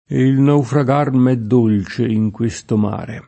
e il naufrag#r m H dd1l©e ij kU%Sto m#re] (Leopardi); antiq. l’elis. in ènclisi: Ma, cipressetti miei, lasciatem’ire [ma, ©ipreSS%tti mL$i, lašš#tem &re] (Carducci) — cfr. me